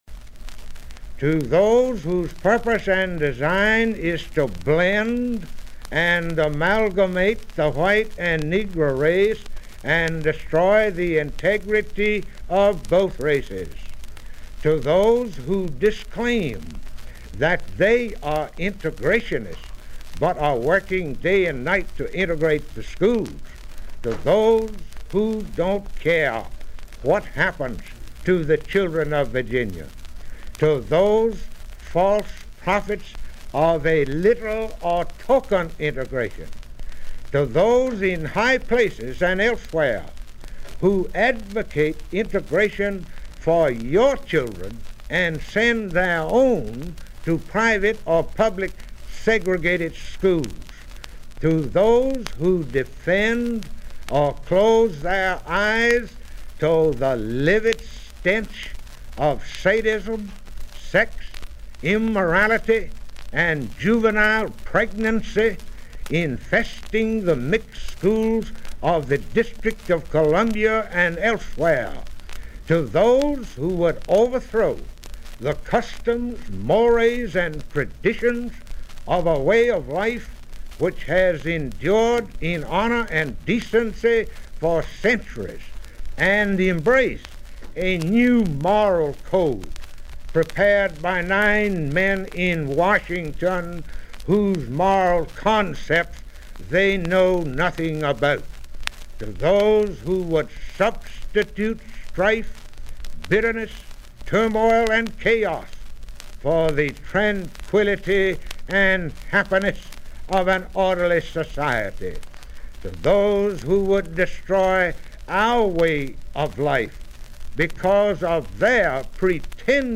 J. Lindsay Almond School Integration Speech, January 20, 1959 · Document Bank of Virginia